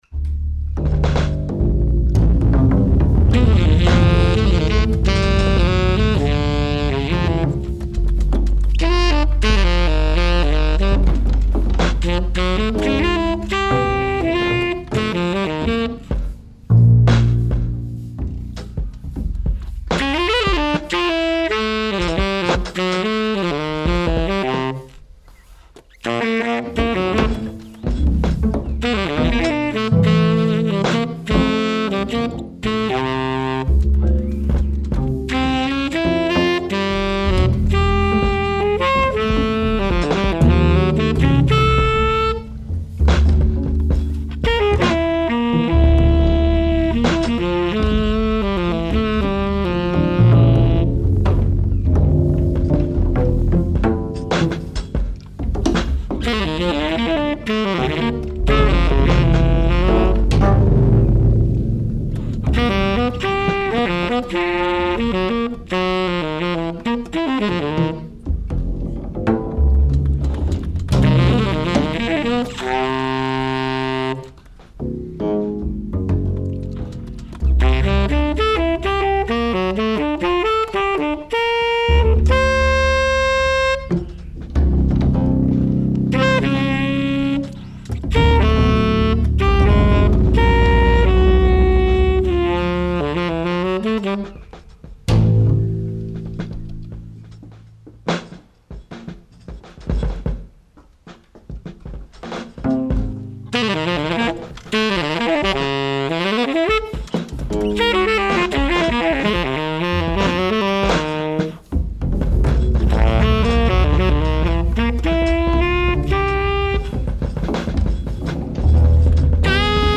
Additional (live mashed) sonic fictions